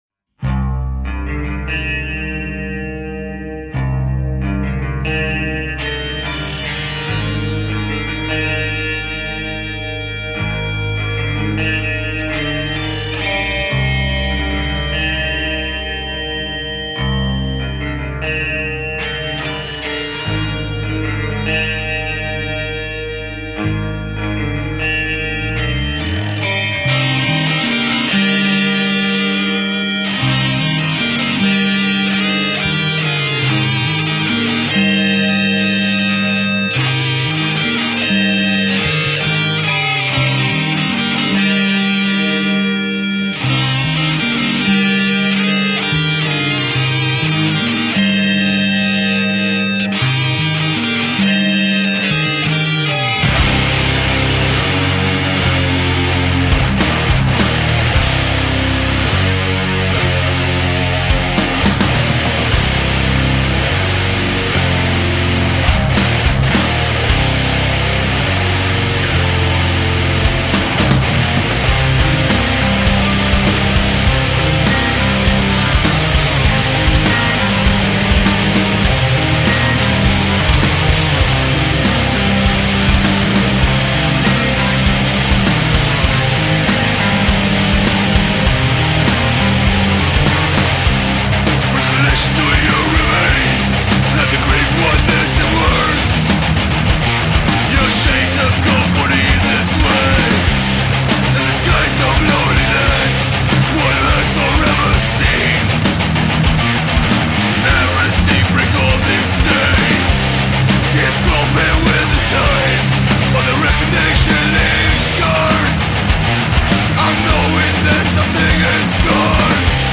Gesang
Bass
Lead-Gitarre
Rythmus-Gitarre
Drums